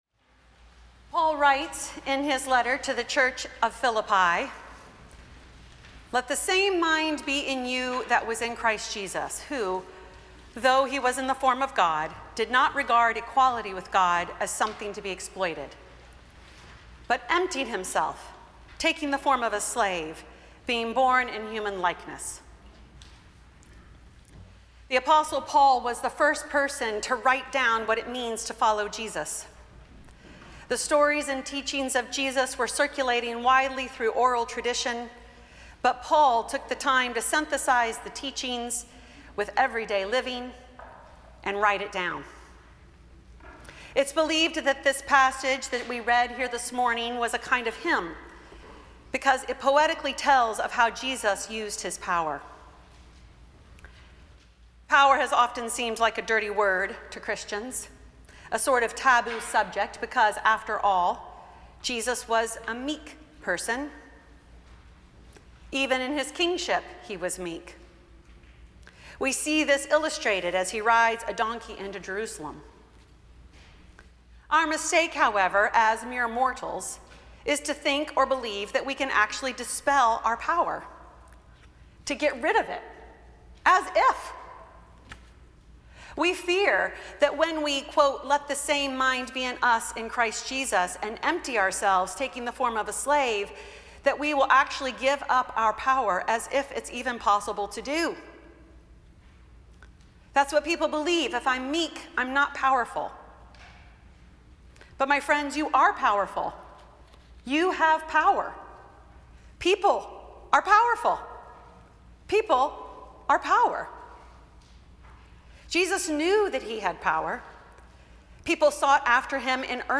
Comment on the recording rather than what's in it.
Each Sunday, we record the sermon from the morning Eucharist at St. Stephens Church in Ridgefield, CT (Episcopal) and publish it as a podcast.